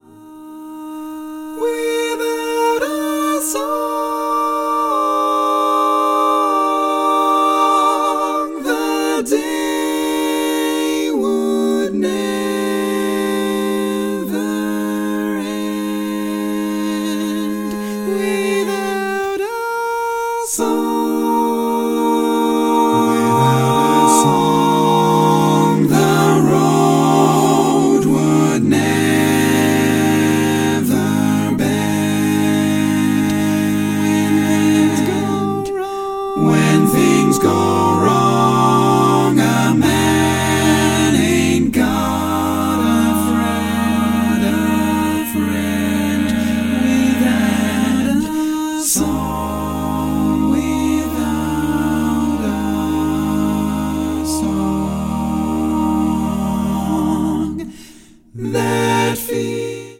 Mixed
SSAATTBB